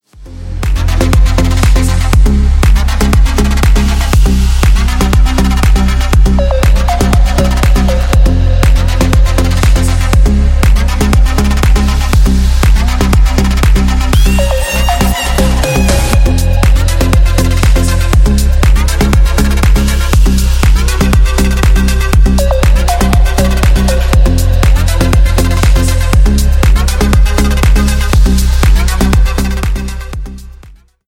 deep house
dance
без слов
future house
club